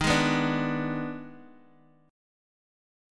EbM7sus4#5 chord